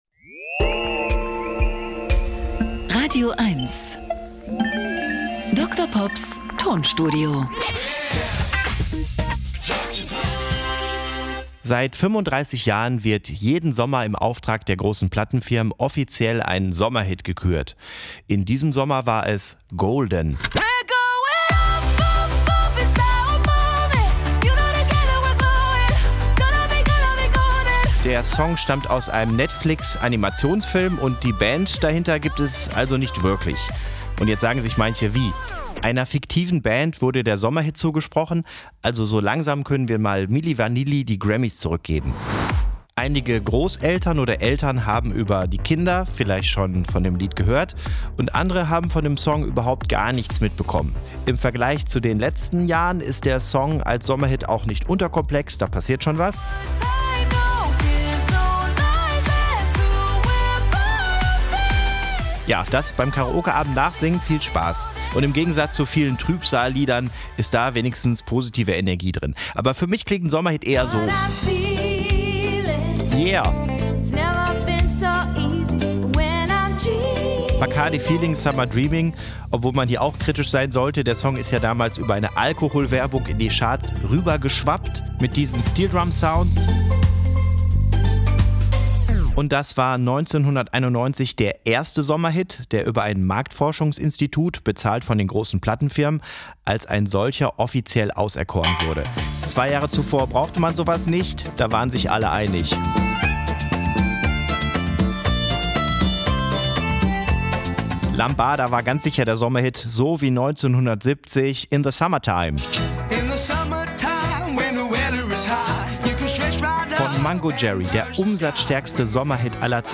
Comedy und Kabarett.
Er therapiert mit Musiksamples und kuriosen, aber völlig wahren Musikfakten.
Comedy